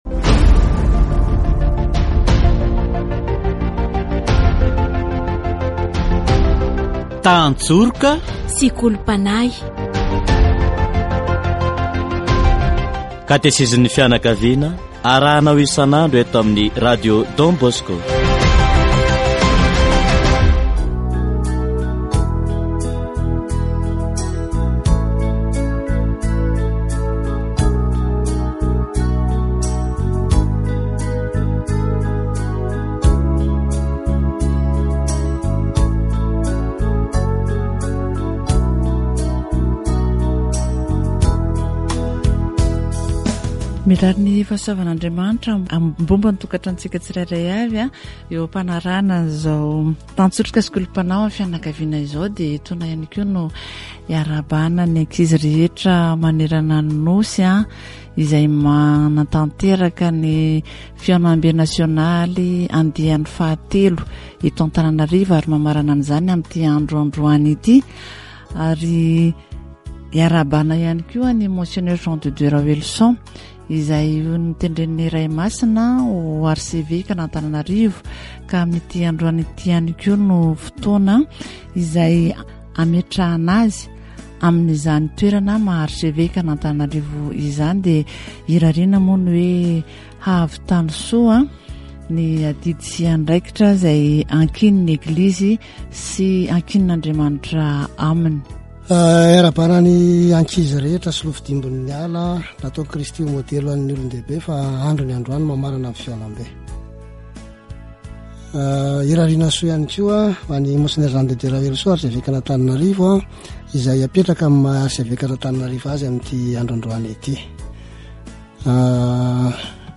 Nisy talohan'ny fahotana ny asa, manaho ny fahalavorarian'Andriamanitra fitiavana. Katesizy momba ny asa